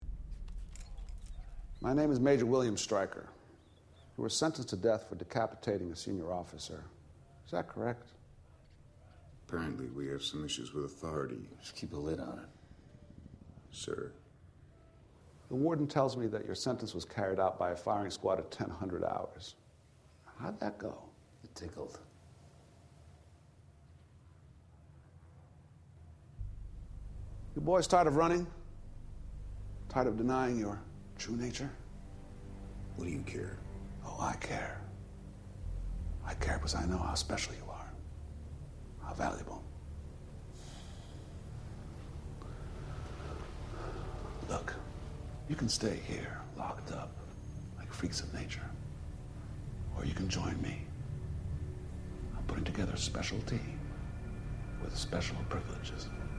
Tags: movie dialog quotes speeches soundtrack